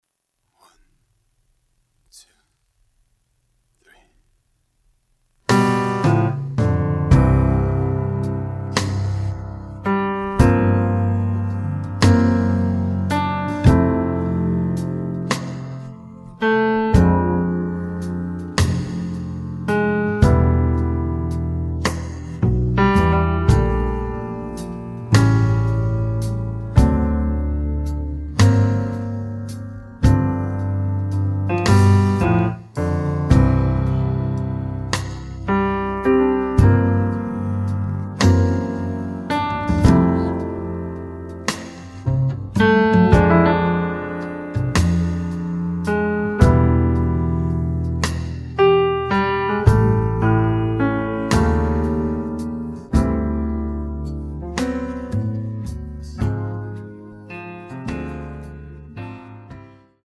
음정 원키 4:07
장르 가요 구분 Voice Cut